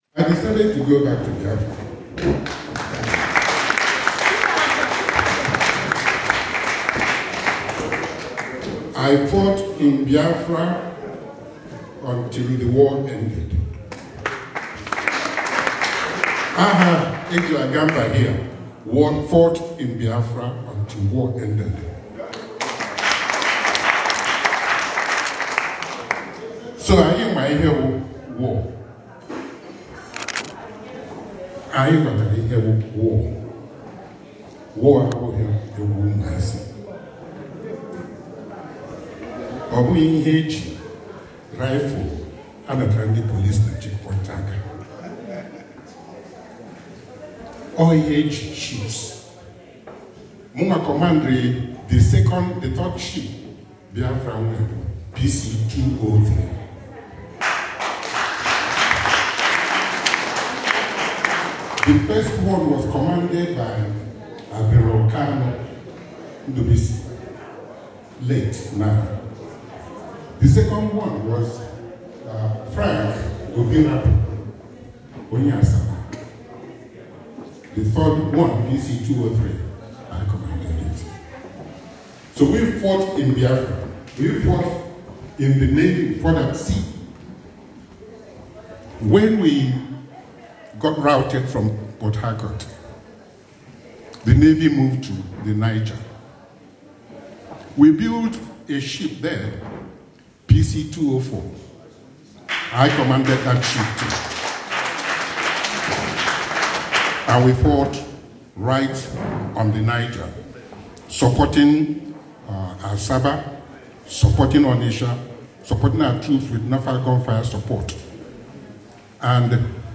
The symbolic event which took place in the RC church in Edmonton North London, saw notable dignitaries in the world of busines, arts, finance, tech, medicine and even ordained traditional title holders of Igboland, flying in to grace the occasion
Audio message of ex gov Madueke below.
Former Governor of old Anambra state rtd Rear Admiral Allison Madueke gives advice to Igbo youths
Ohaneze-London-Chief-Allison-madueke.ex-Anambra-Gov-.ogg